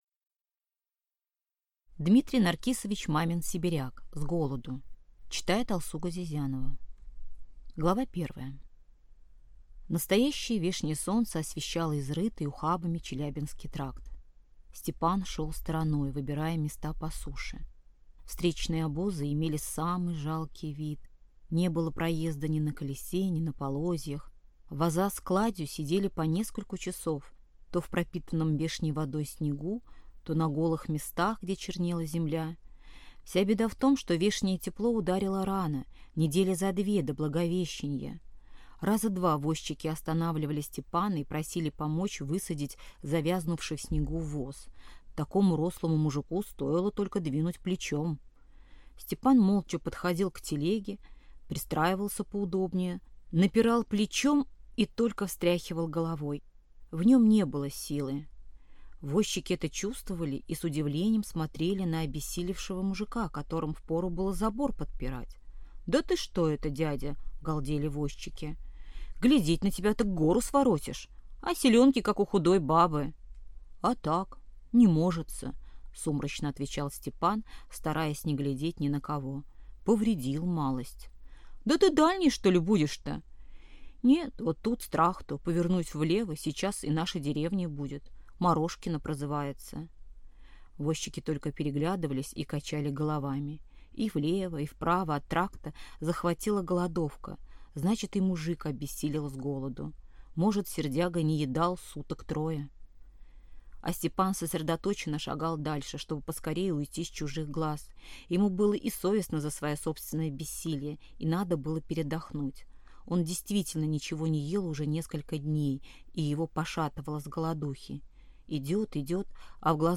Аудиокнига С голоду | Библиотека аудиокниг